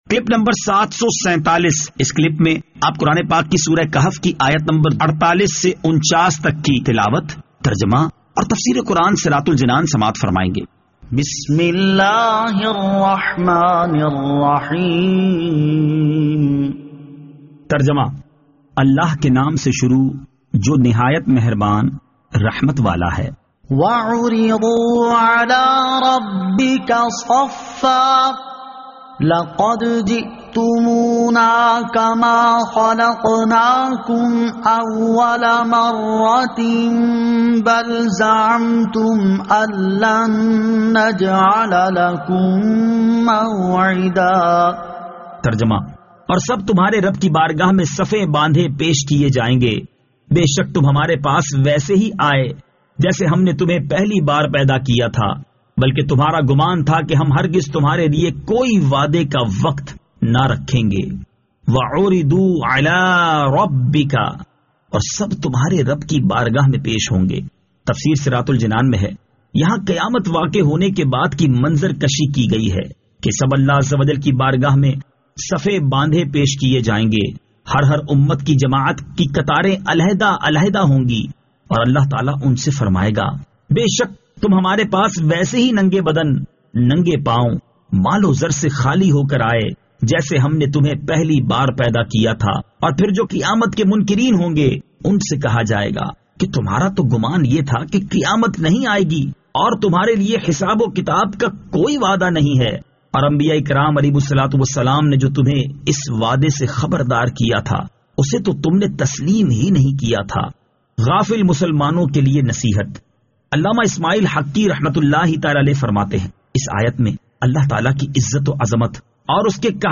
Surah Al-Kahf Ayat 48 To 49 Tilawat , Tarjama , Tafseer